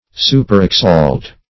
Search Result for " superexalt" : The Collaborative International Dictionary of English v.0.48: Superexalt \Su`per*ex*alt"\, v. t. [imp.